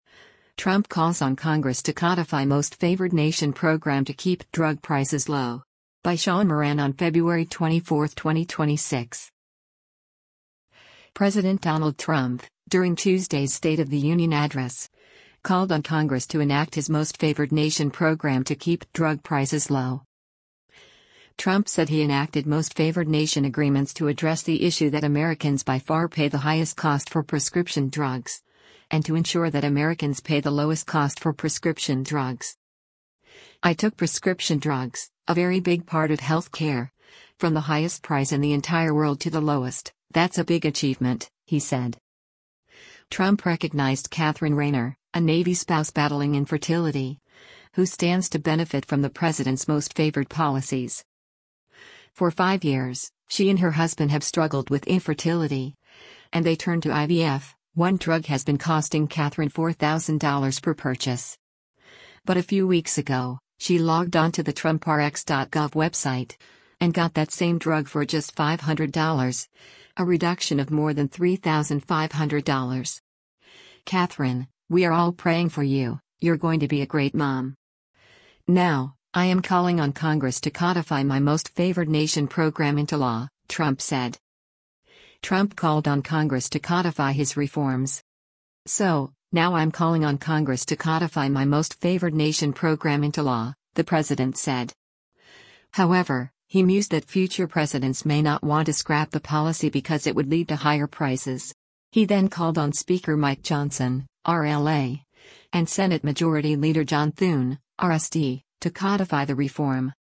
President Donald Trump, during Tuesday’s State of the Union address, called on Congress to enact his most favored nation program to keep drug prices low.